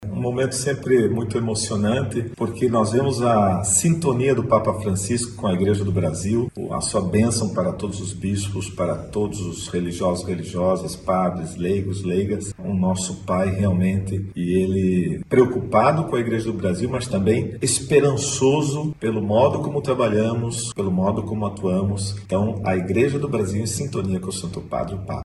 Além das visitas aos dicastérios, a presidência da CNBB teve a oportunidade de se reunir com o Papa Francisco. O encontro proporcionou um momento de partilha e comunhão, permitindo que os bispos apresentassem o trabalho realizado pela Conferência e compartilhassem as realidades do Brasil, com suas alegrias e desafio, destaca o Secretário-geral da CNBB, Dom Ricardo Hoepers.